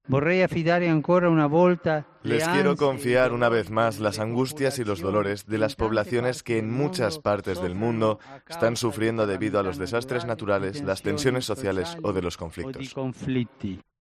El Papa Francisco ha pronunciado estas palabras desde la ventana del palacio apostólico del Vaticano y momentos después de rezar el Ángelus, con motivo de la celebración de la Asunción de la Virgen.